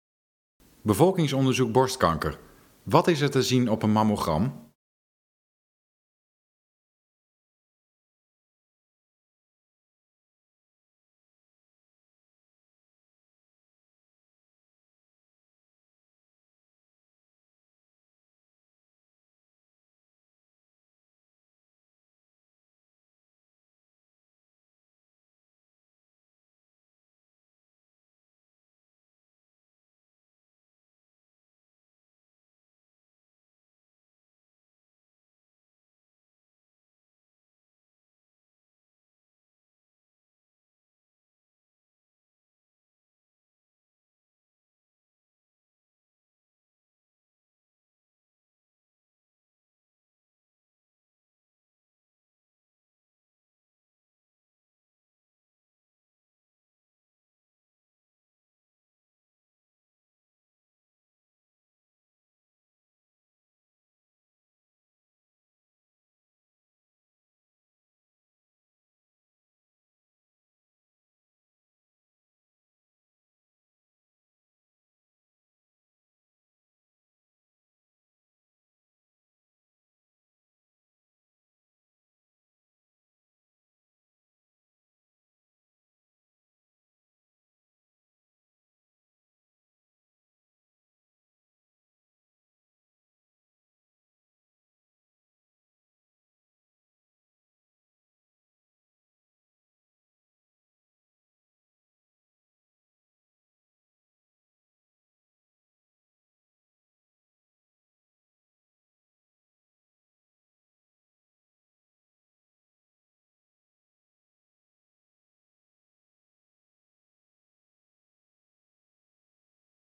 In deze video vertelt een radioloog wat er te zien is op een mammogram die gemaakt is bij het bevolkingsonderzoek borstkanker.